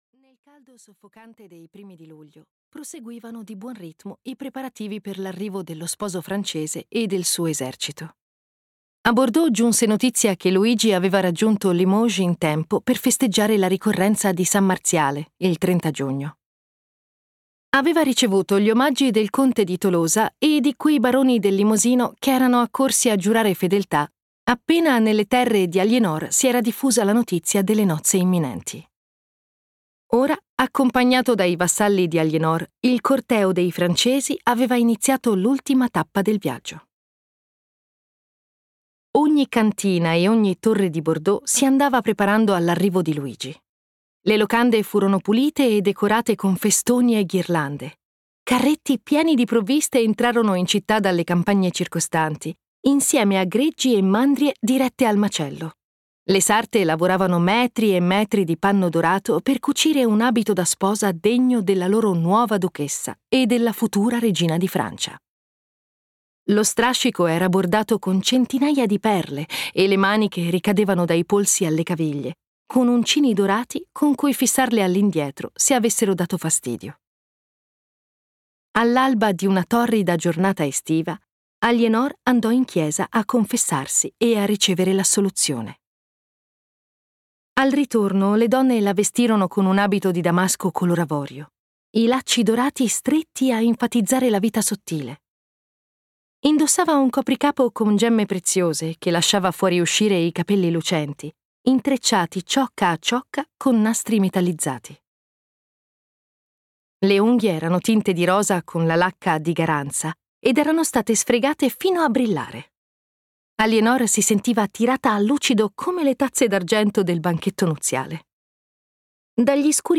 "La regina ribelle" di Elizabeth Chadwick - Audiolibro digitale - AUDIOLIBRI LIQUIDI - Il Libraio